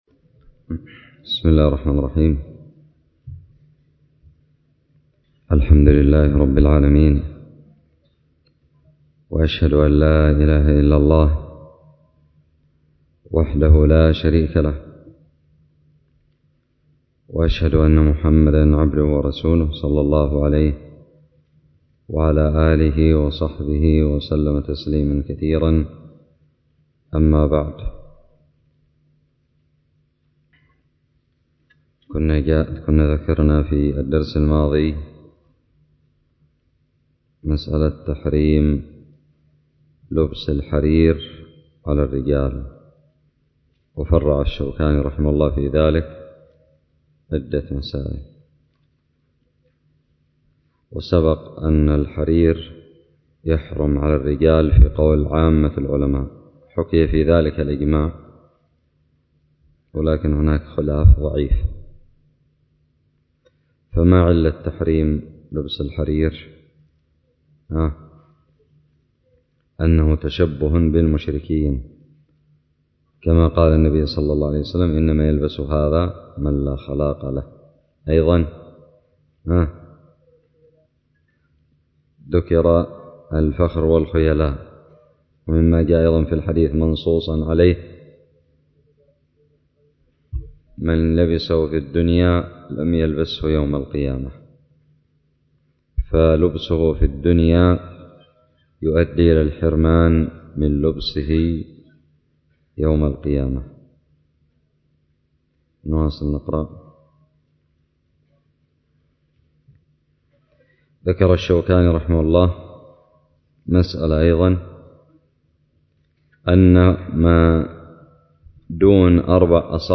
الدرس الرابع من كتاب اللباس من الدراري
ألقيت بدار الحديث السلفية للعلوم الشرعية بالضالع